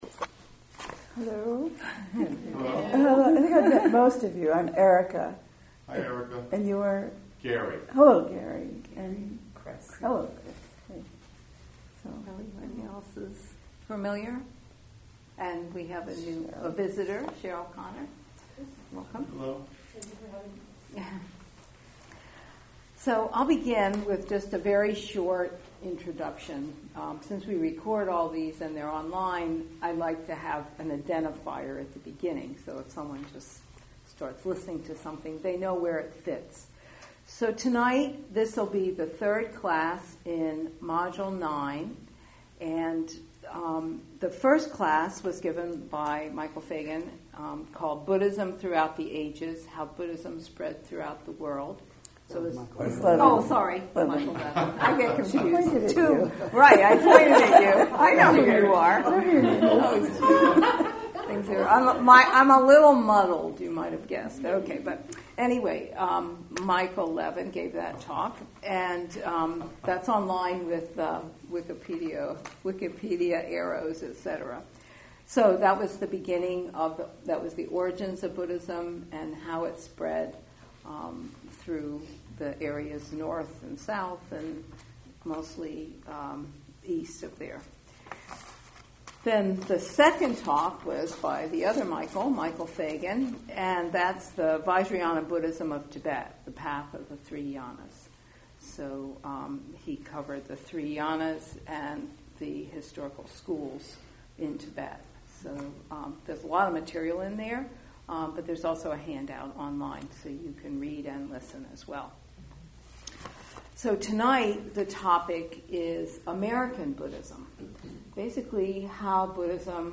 The Vajrayana Buddhism of Tibet – The Path of the Three Yanas Audio of Talk